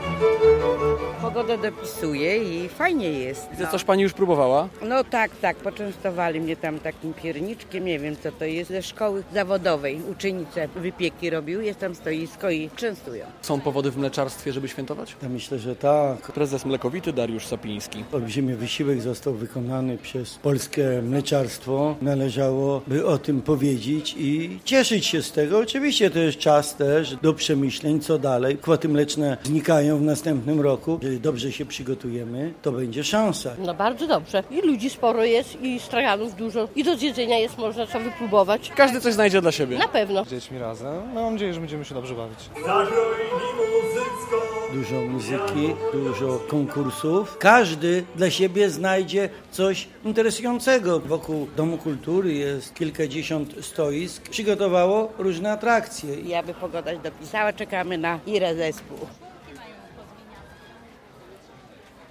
Światowy Dzień Mleka - relacja